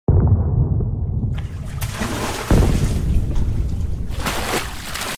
Iceland Volcano Eruption 🌋 Sound Effects Free Download